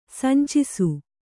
♪ sancisu